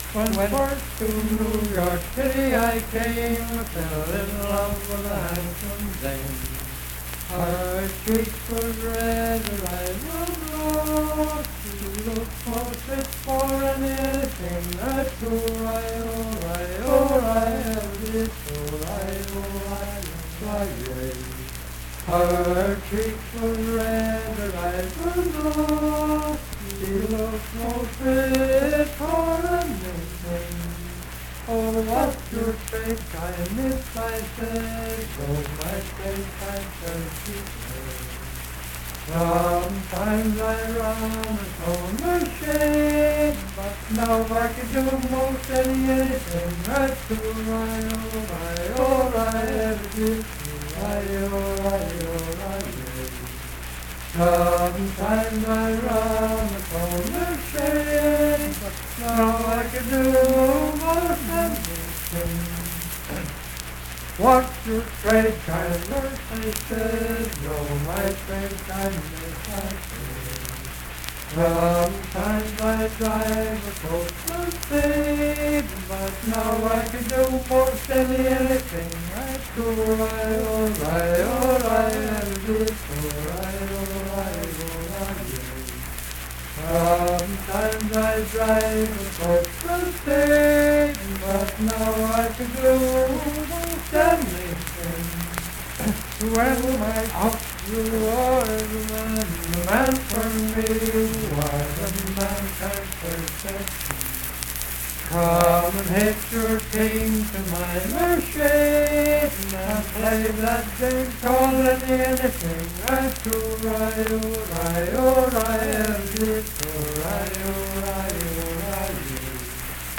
Unaccompanied vocal music
Richwood, Nicholas County, WV.
Bawdy Songs
Voice (sung)